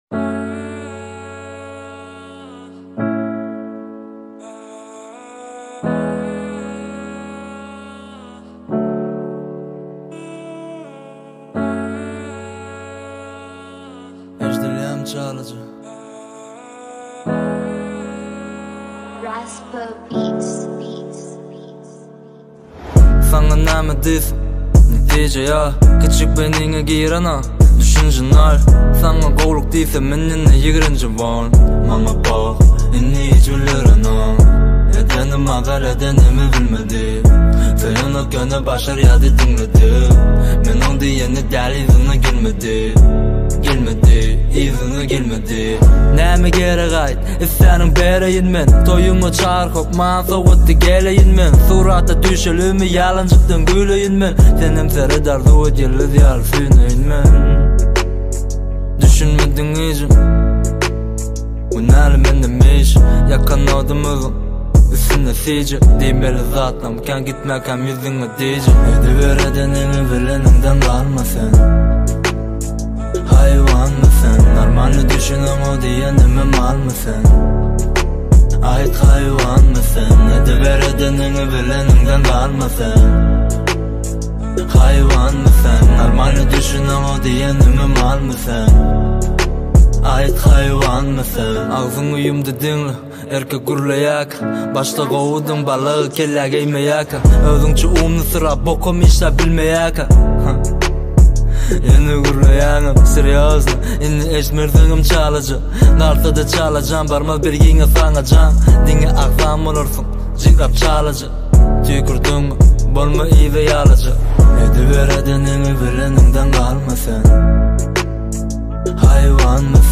BilyanmHawan_TmRapHipHop_.mp3